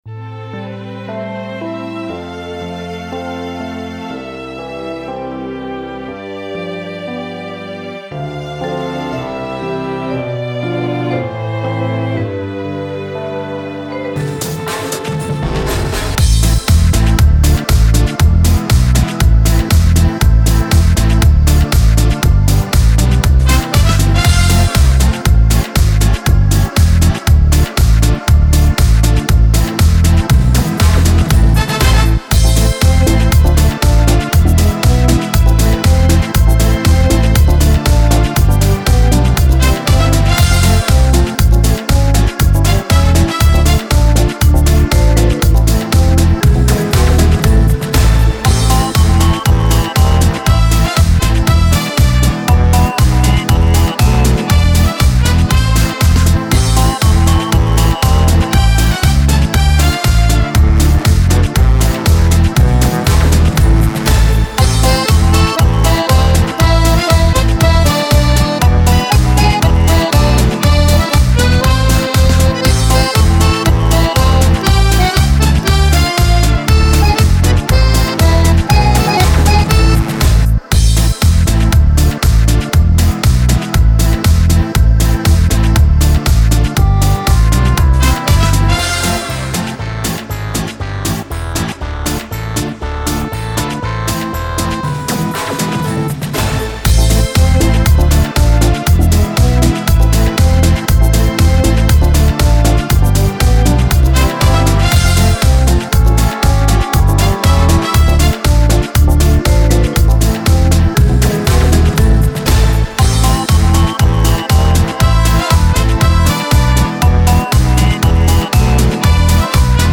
ГлавнаяПесниПесни про Новый год